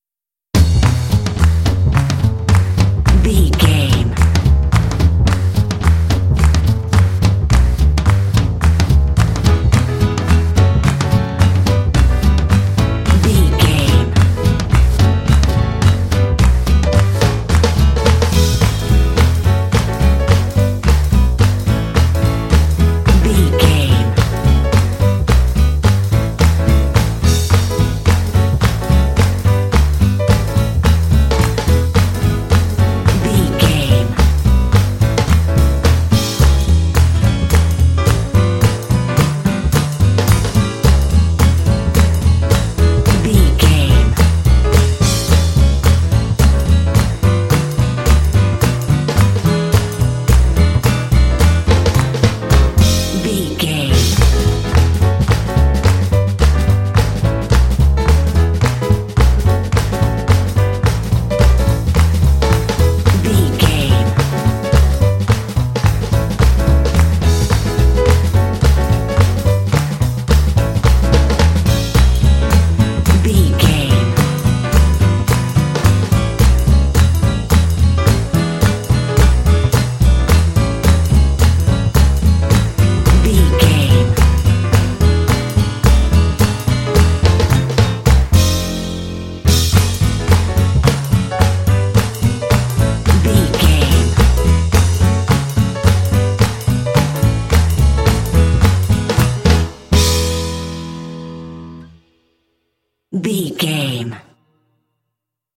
Uplifting
Mixolydian
Fast
energetic
bouncy
joyful
double bass
drums
piano
big band
jazz